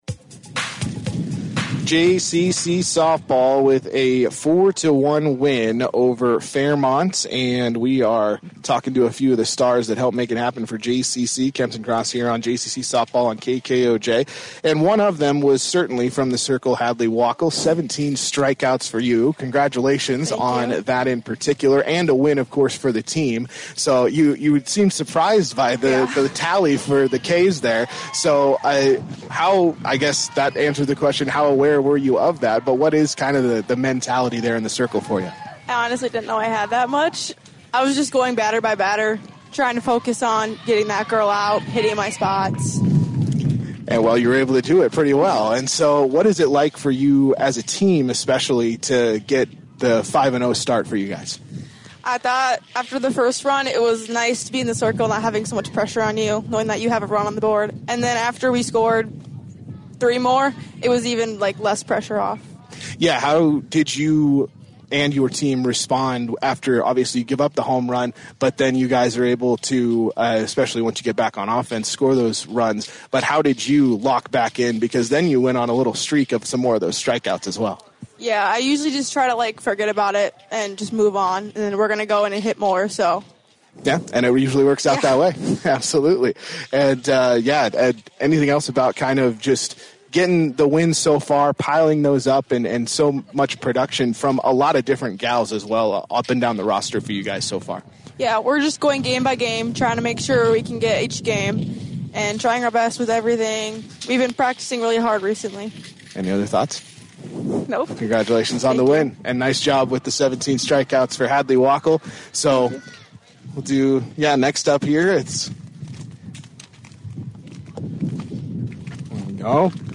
4-21-25-jcc-sb-vs-fairmont-post-game-show.mp3